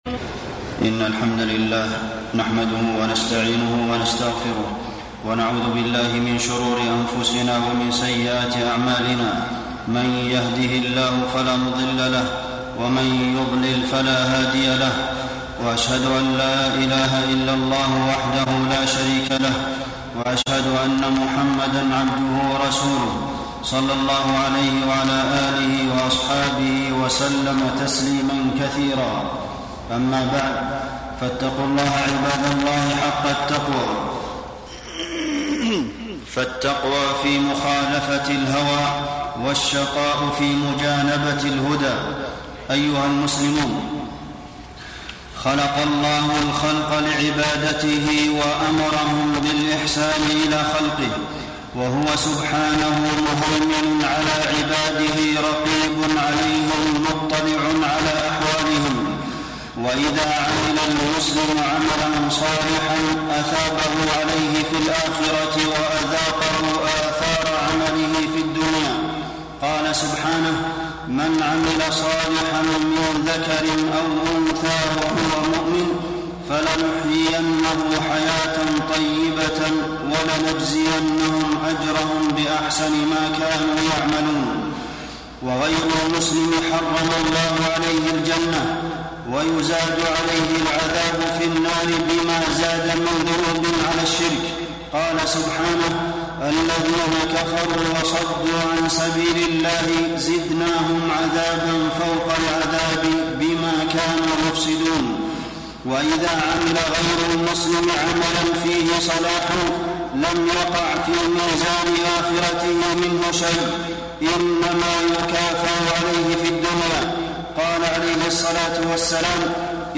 خطب الحرم المكي